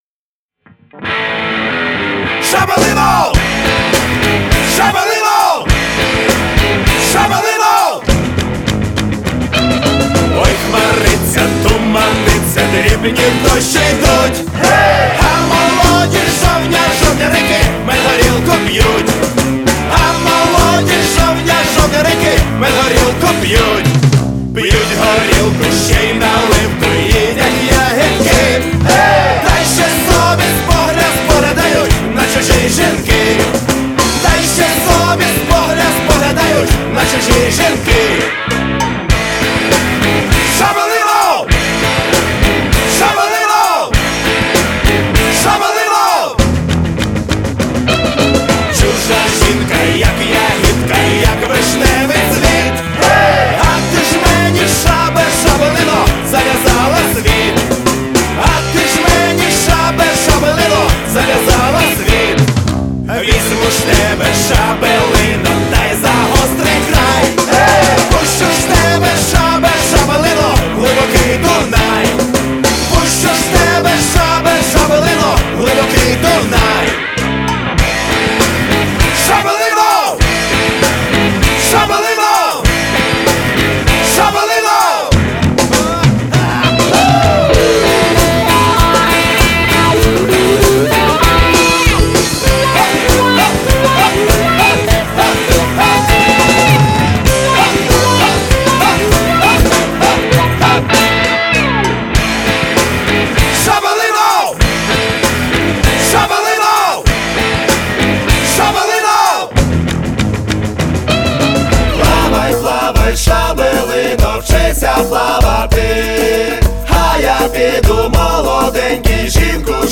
Категорія: Rock